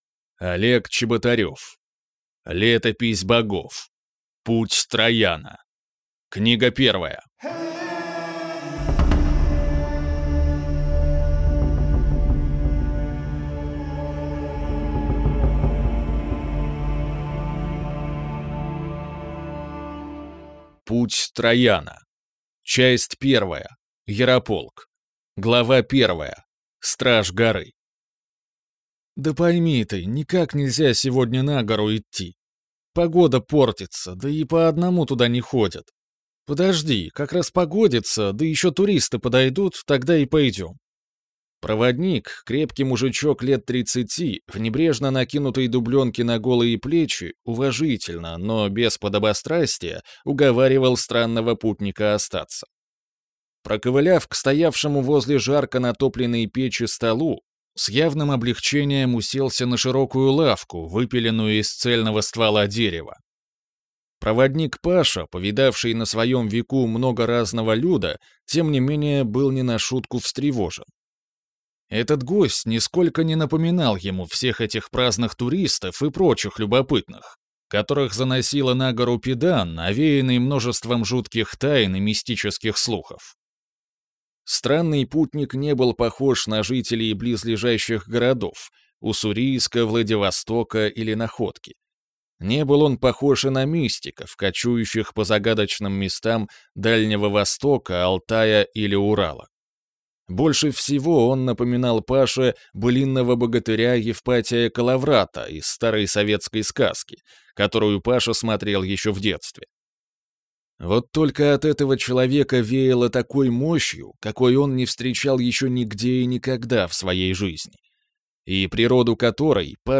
Аудиокнига Летопись богов. Путь Трояна | Библиотека аудиокниг